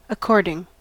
Ääntäminen
US : IPA : /ə.ˈkɔɹd.ɪŋ/